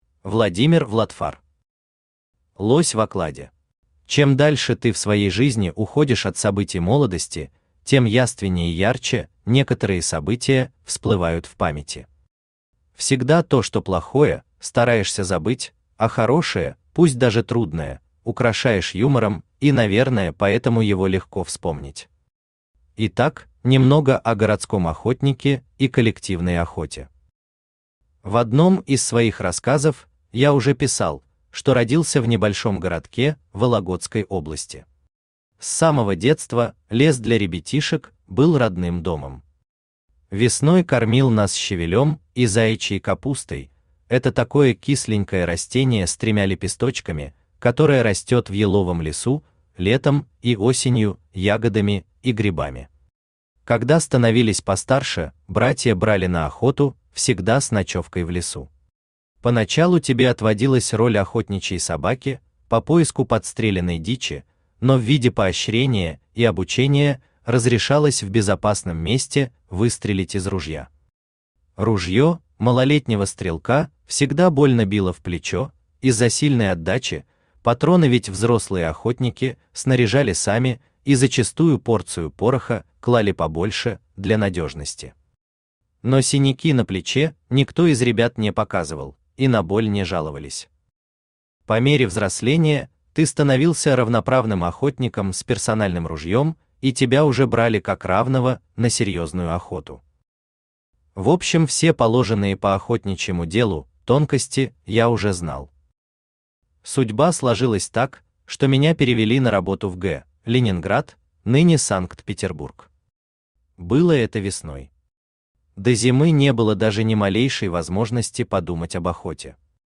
Аудиокнига Лось в окладе | Библиотека аудиокниг
Aудиокнига Лось в окладе Автор Владимир Vladfar Читает аудиокнигу Авточтец ЛитРес.